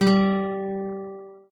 guitar_g.ogg